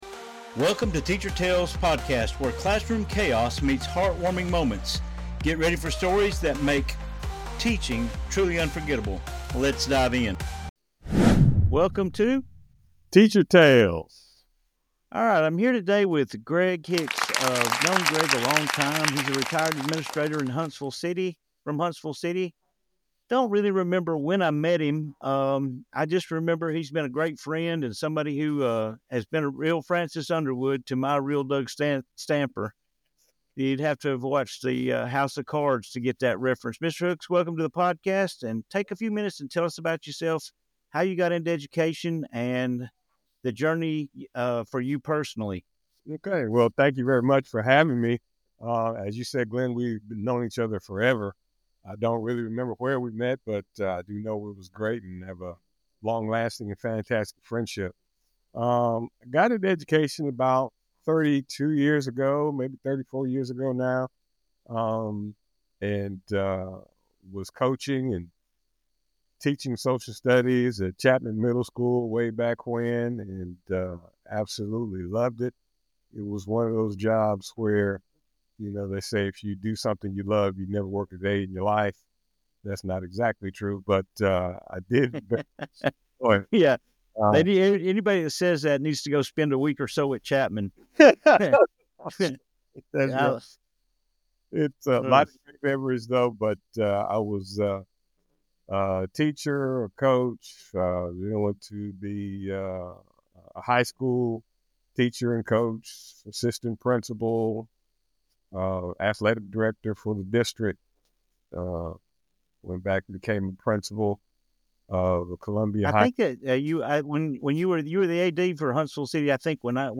😇😈 Join us for a fun and insightful conversation as we dive into the highs, lows, and laughter of life in education.